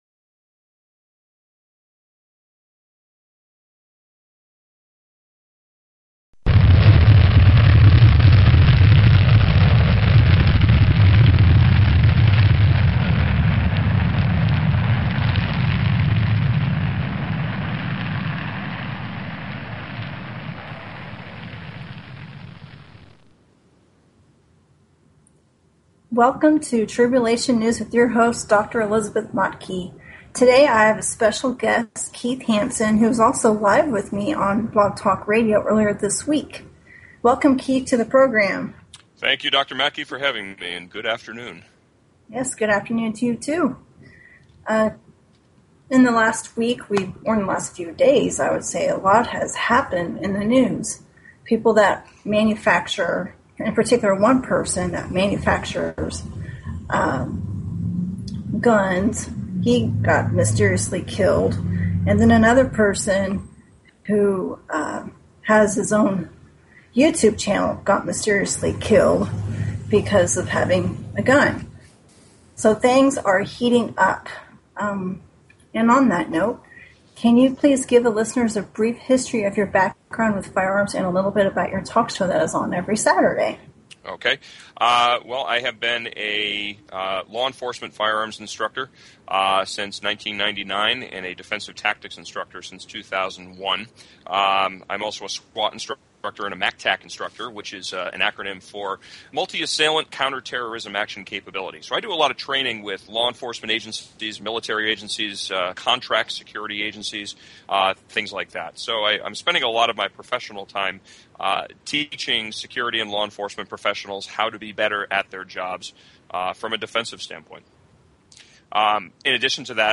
Talk Show Episode, Audio Podcast, Tribulation_News and Courtesy of BBS Radio on , show guests , about , categorized as